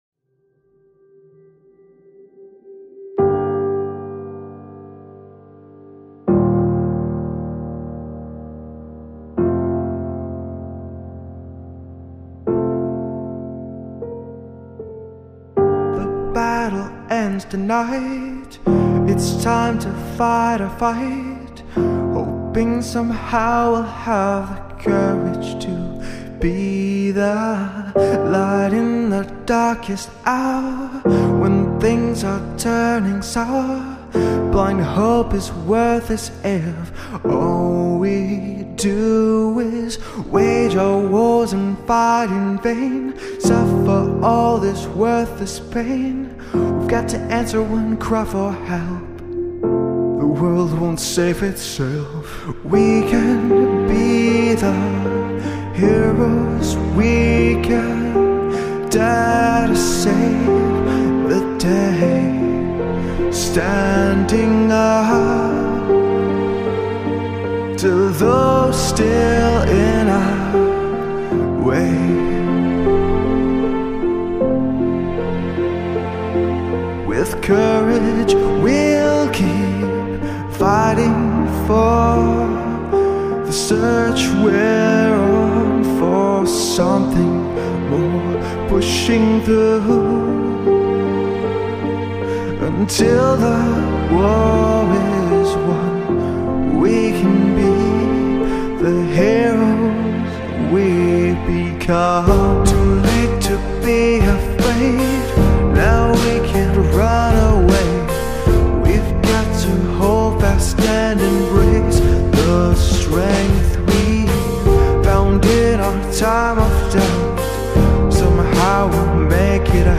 including the Friendship acoustic version